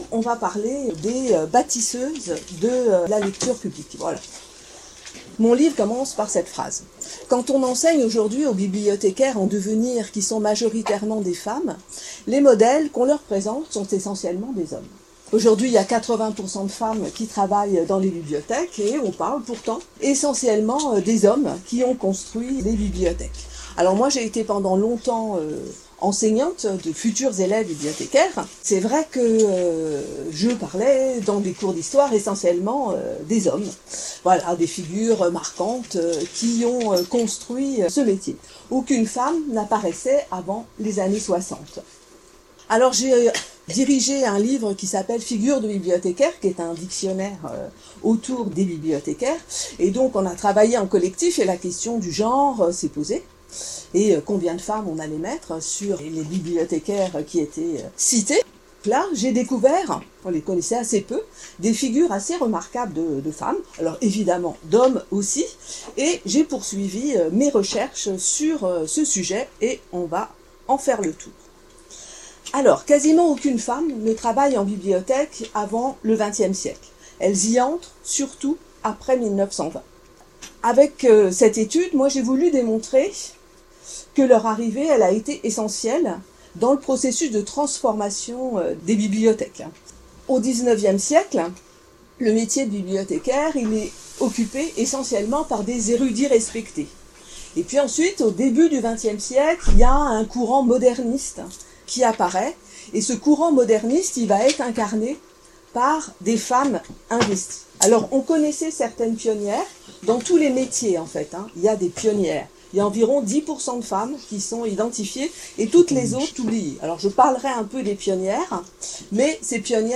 17h Cette conférence, illustrée de nombreuses photos, raconte une histoire méconnue : la lente intégration des femmes dans l’univers des bibliothèques. Elle éclaire sur les facteurs d’accélération et aussi sur les résistances.